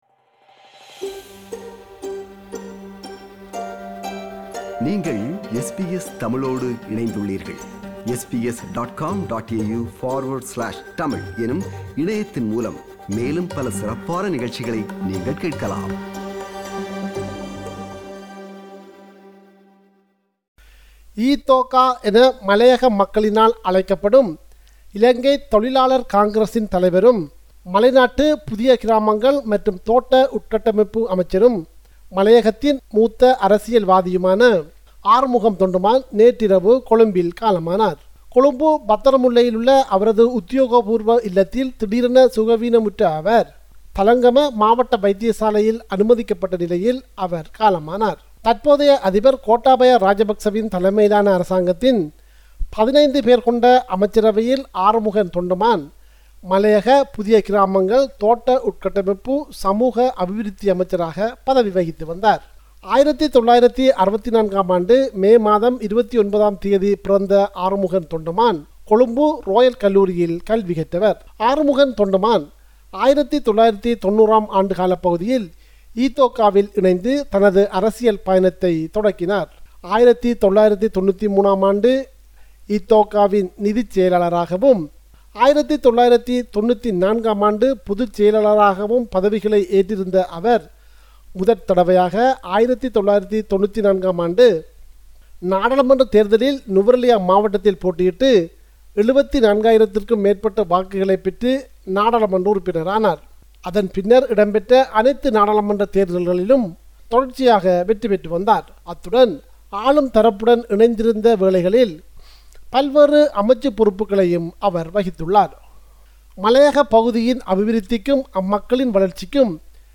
our correspondent in Sri Lanka, compiled a report focusing on major events/news in North & East / Sri Lanka.